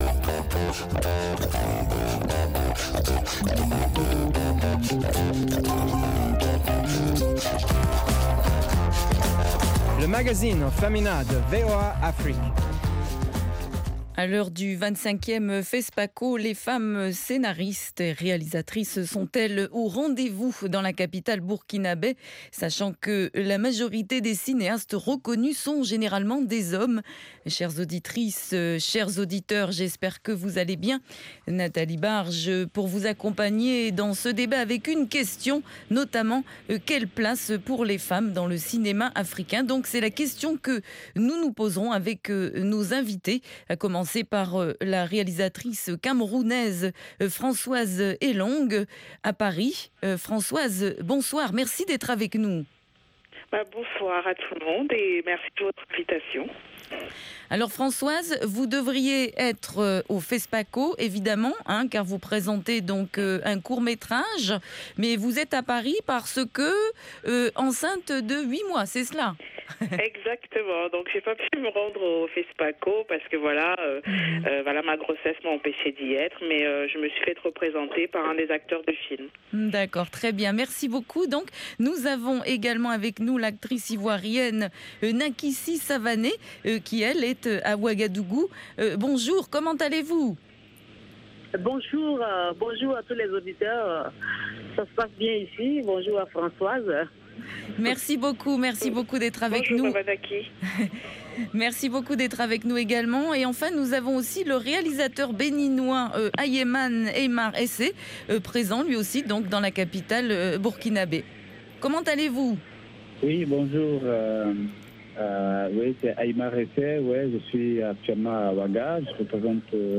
LMF présente également des reportages exclusifs de nos correspondants sur le continent.